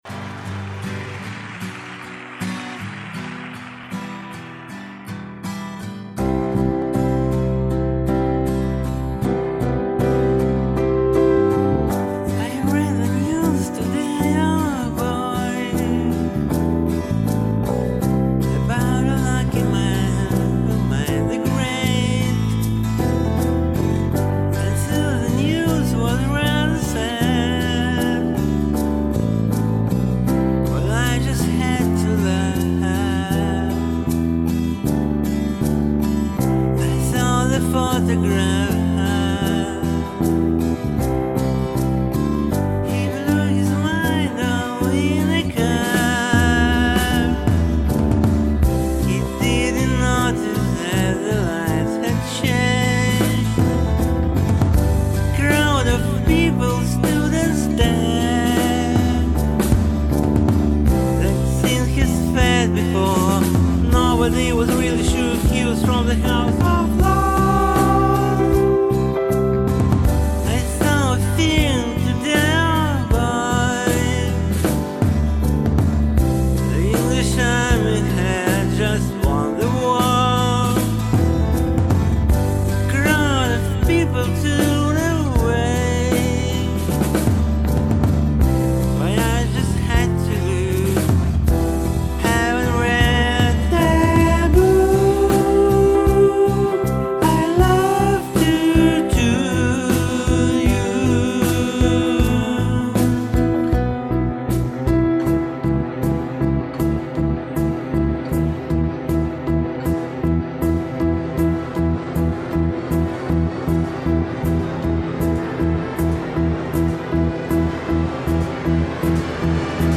Здесь, вроде, как я